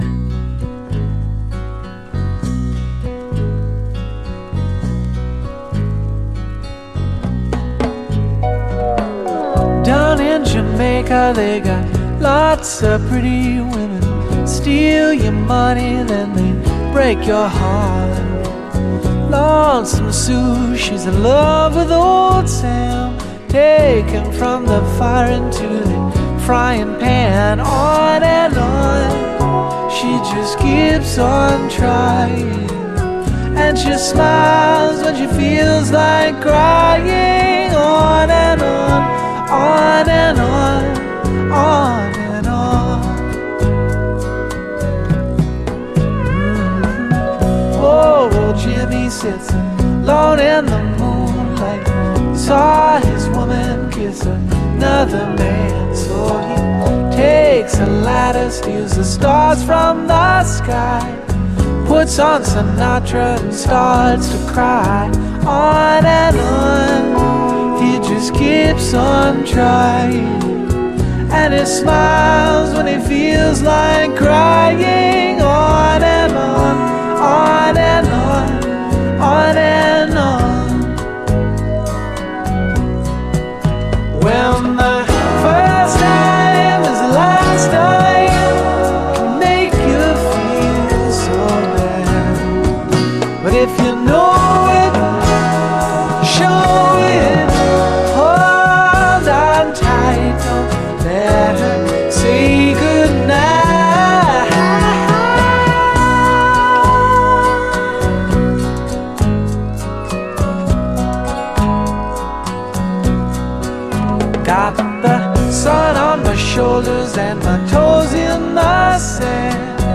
シンセ・サウンドが気持ちいいトロピカル・ラテン・ダンサー
バレアリックなスロウ・トラックもビューティフルです！